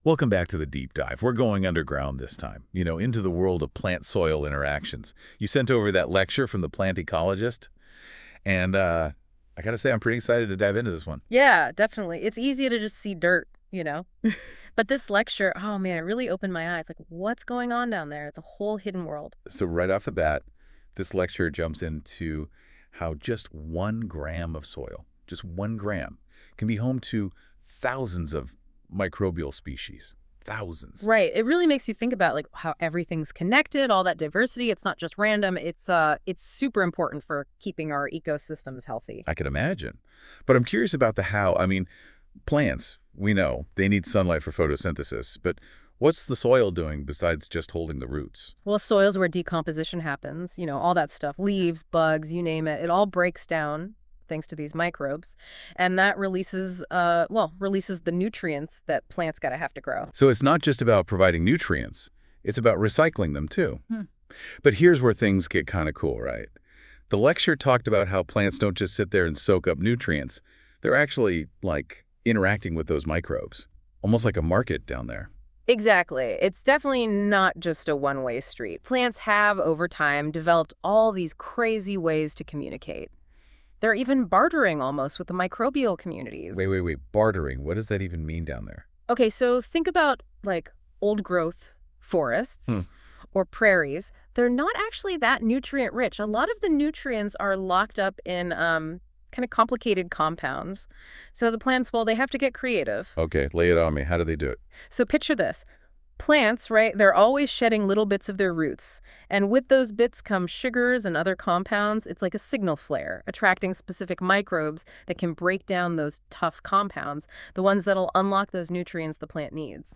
Finally, the Q&A session explores practical applications of this knowledge in ecological restoration and the challenges of restoring degraded landscapes.